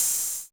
JJHiHat (14).wav